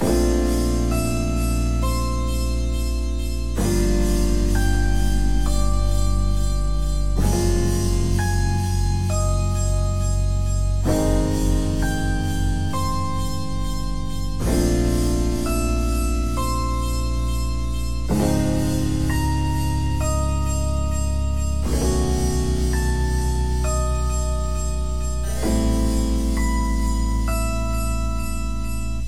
一架FenderRhodes电钢琴弹出了一些冥想的和弦。
Tag: 66 bpm Chill Out Loops Piano Loops 4.90 MB wav Key : A